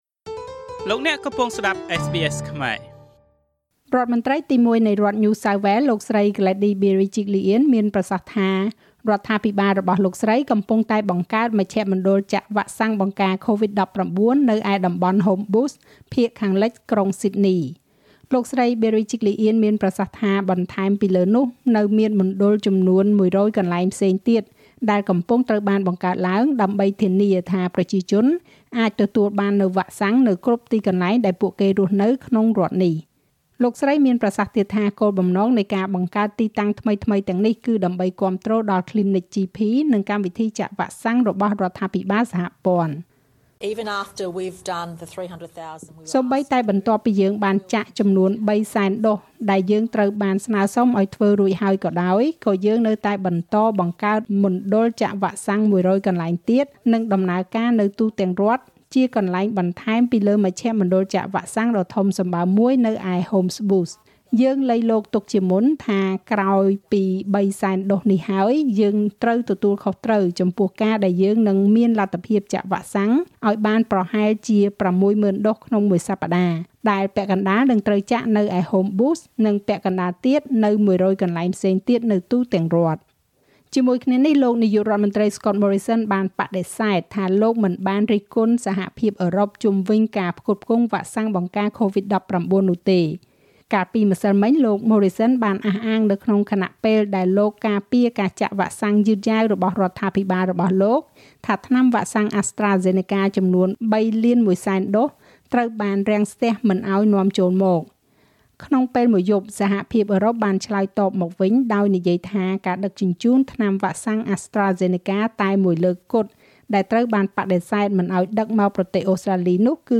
នាទីព័ត៌មានរបស់SBSខ្មែរ សម្រាប់ថ្ងៃពុធ ទី៧ ខែមេសា ឆ្នាំ២០២១។